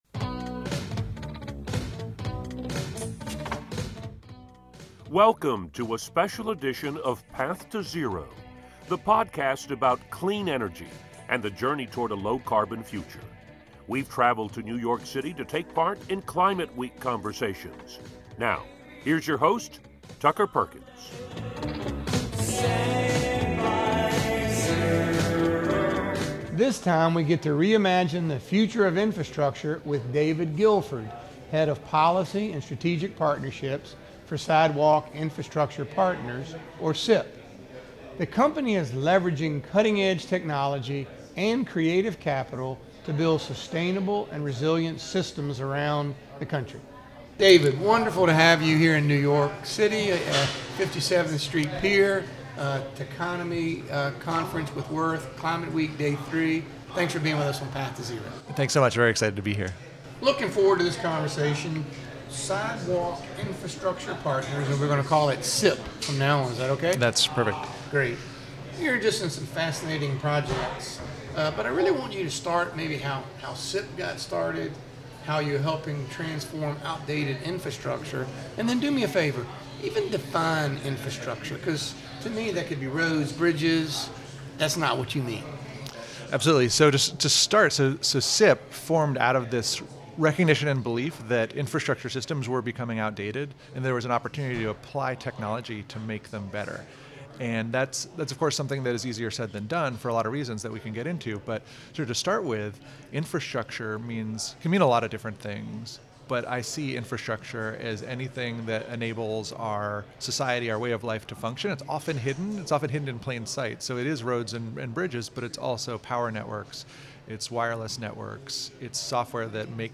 From the Techonomy Climate event at City Winery, the episode dives deep into how SIP is revolutionizing infrastructure with cutting-edge technology and creative capital.